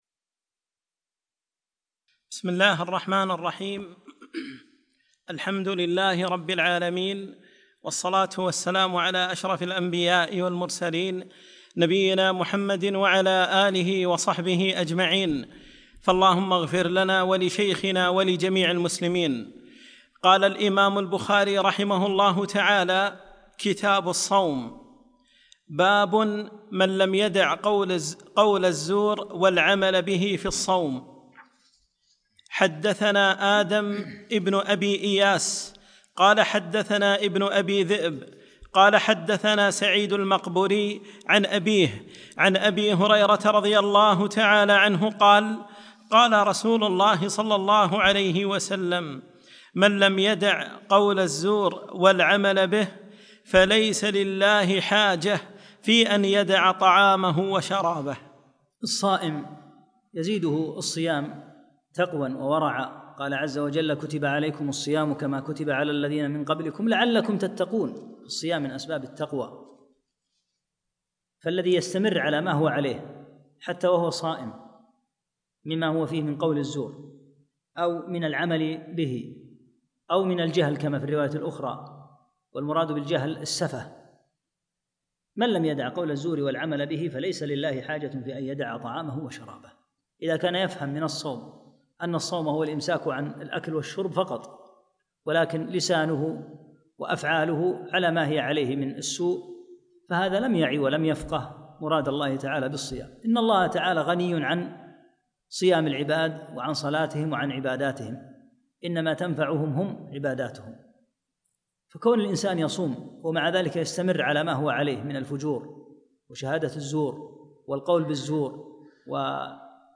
2- الدرس الثاني